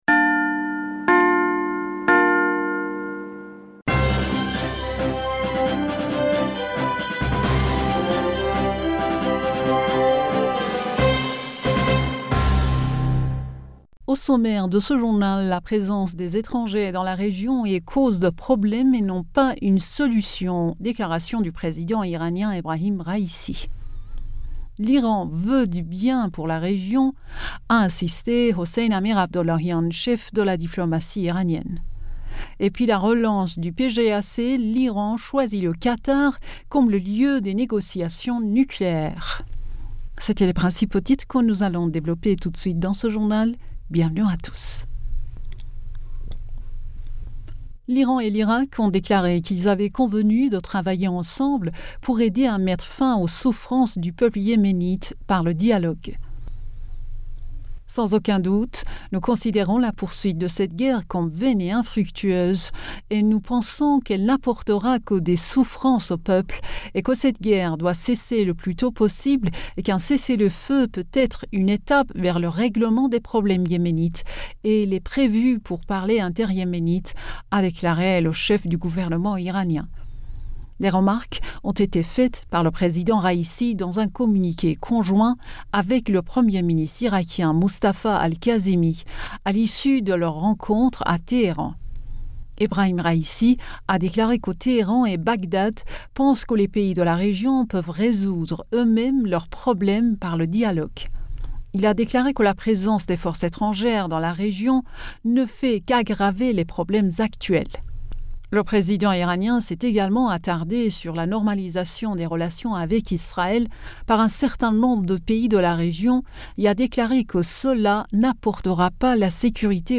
Bulletin d'information Du 27 Juin